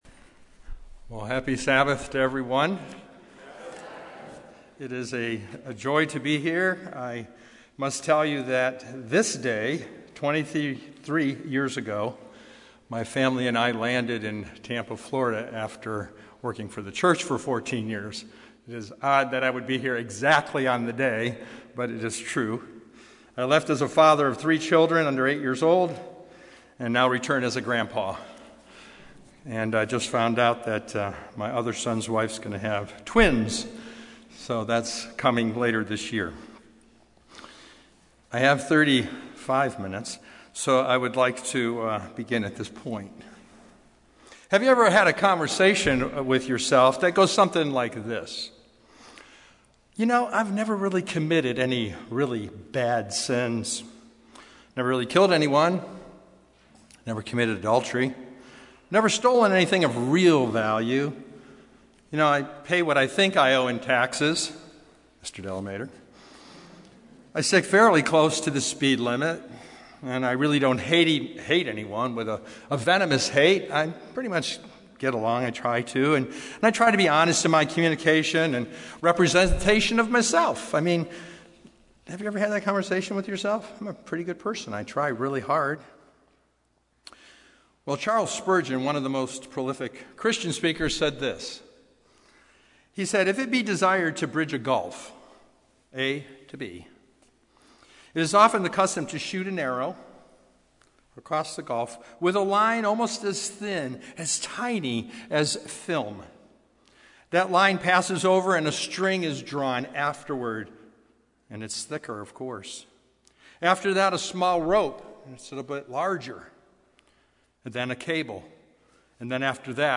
Sermons
Given in Los Angeles, CA